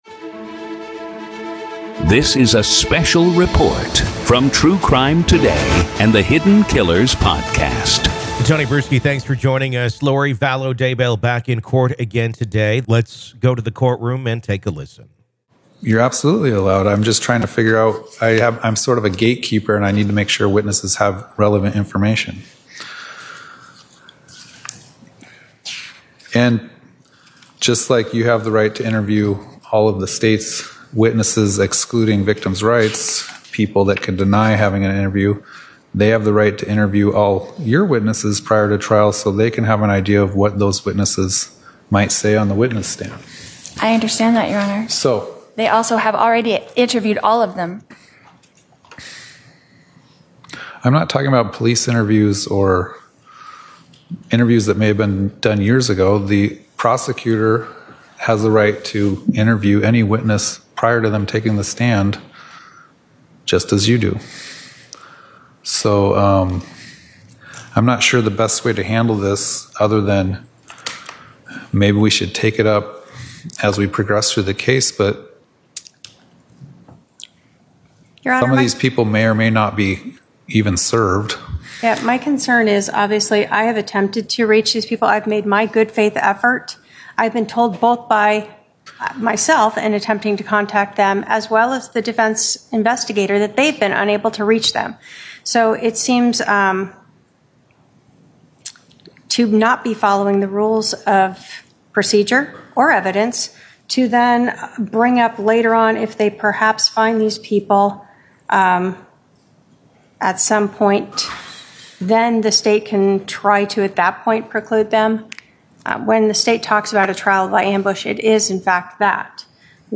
COURT AUDIO: AZ VS LORI DAYBELL DAY 2 JURY SELECTION PART 2